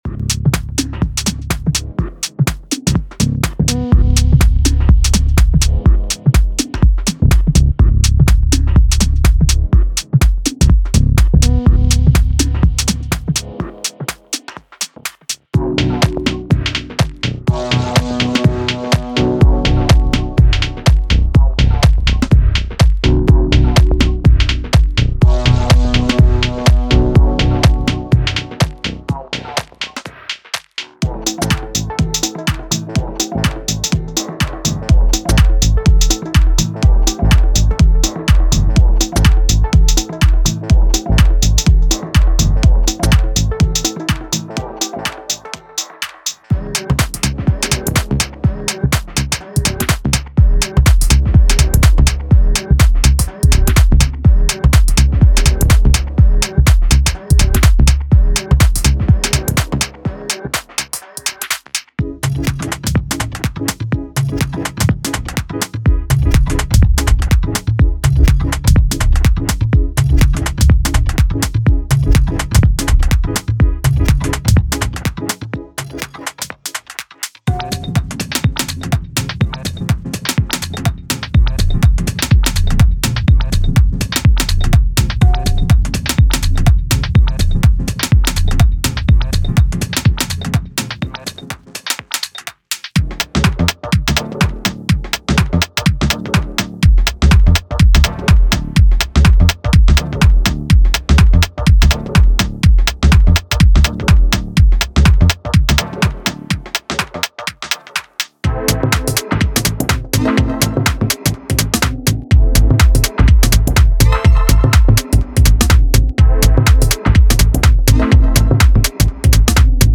Genre:Minimal Techno
デモサウンドはコチラ↓